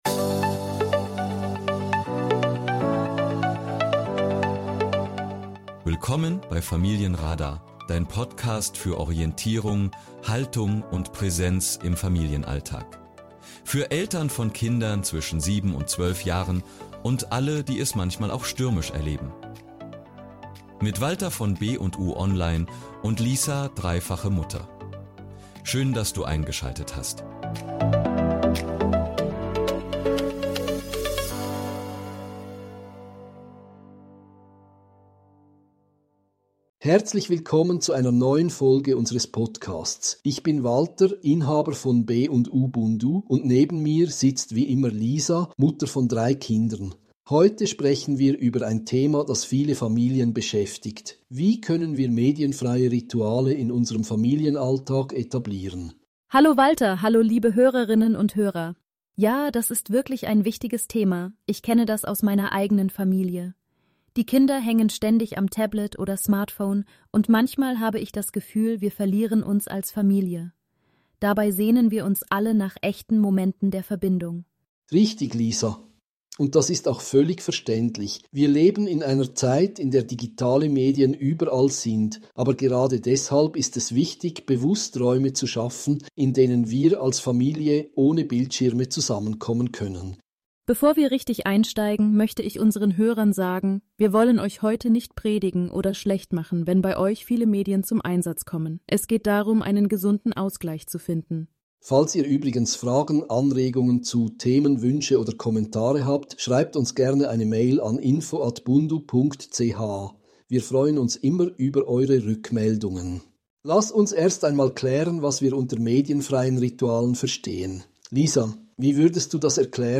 Das Gespräch basiert auf den Prinzipien der Neuen Autorität und bietet praktische Tipps für den Familienalltag.